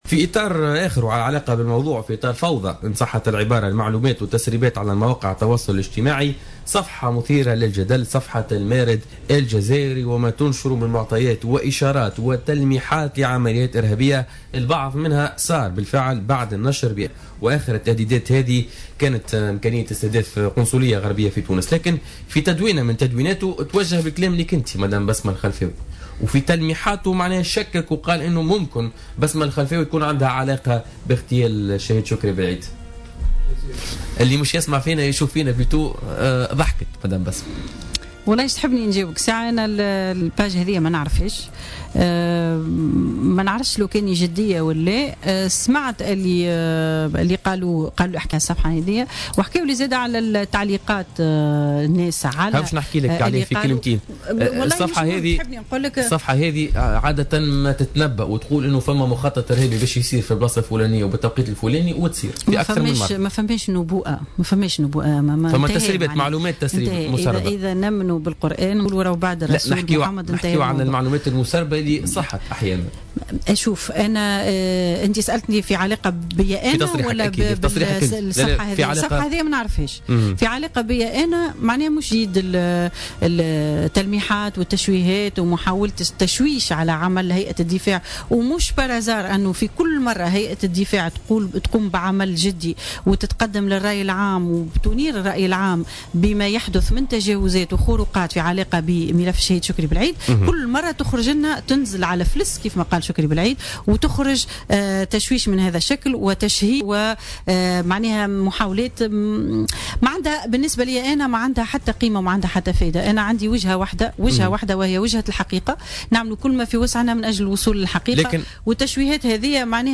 ردت بسمة الخلفاوي أرملة الشهيد شكري بلعيد وضيفة برنامج بوليتكا ليوم الجمعة 23 أكتوبر 2015 على من يتهمونها بالتورط في اغتيال بلعيد.